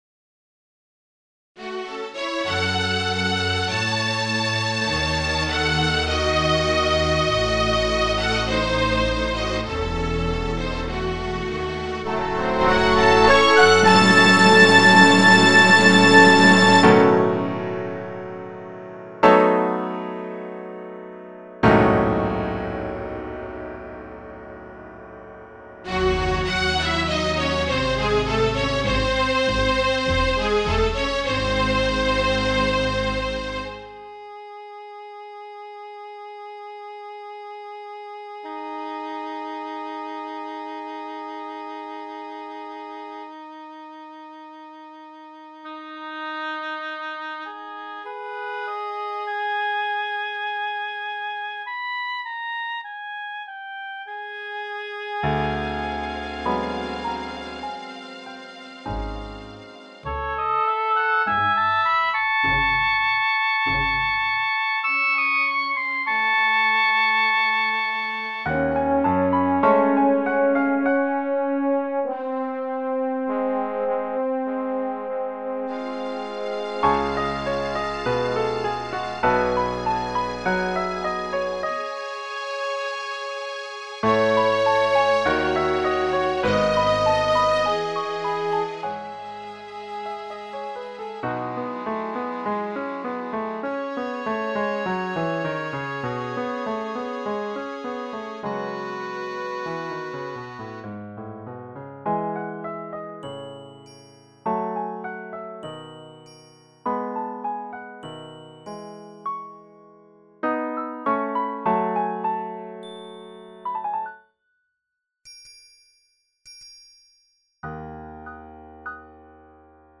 concerto pour piano et orchestre n° 1
concerto_piano_n_1_2eme_mouvement.mp3